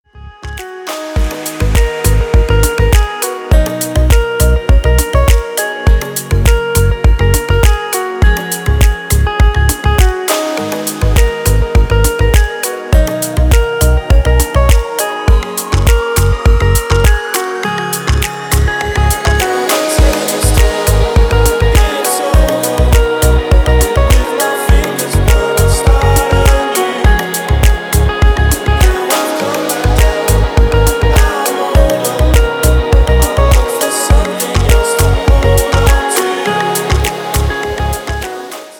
• Качество: 320, Stereo
гитара
мужской голос
remix
deep house
мелодичные
Electronic
спокойные
расслабляющие
Indietronica
Стиль: deep house